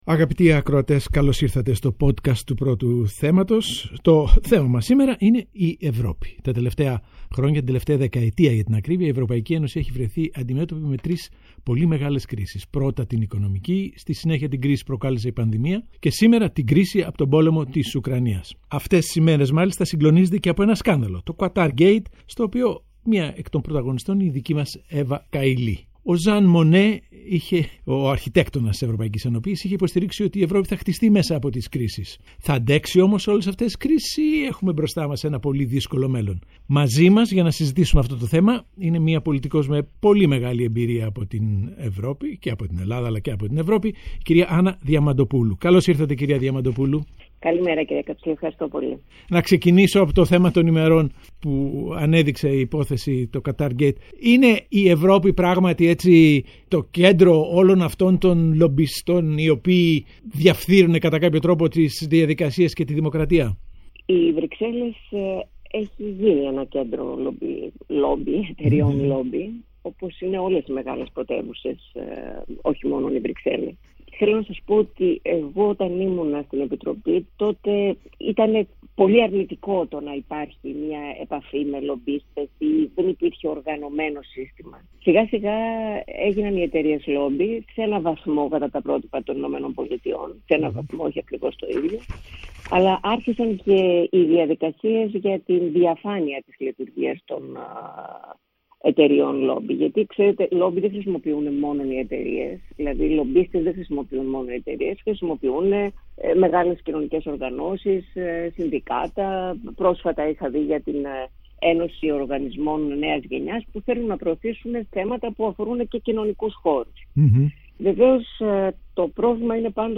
Ο Παντελής Καψής συζητά με την Άννα Διαμαντοπούλου: Τα λόμπι των Βρυξελών και τα think tanks της Μεσογείου